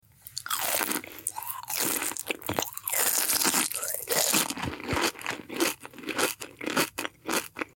ASMR Satisfying Eating Crunchy Sounds